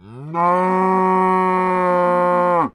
cow-moo-1.ogg